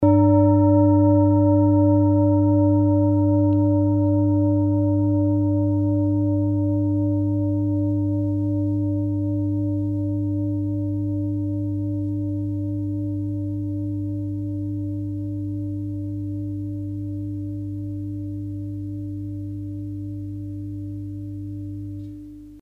Klangschale Bengalen Nr.37
Klangschale-Durchmesser: 28,5cm
Sie ist neu und wurde gezielt nach altem 7-Metalle-Rezept in Handarbeit gezogen und gehämmert.
klangschale-ladakh-37.mp3